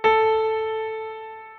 piano3.wav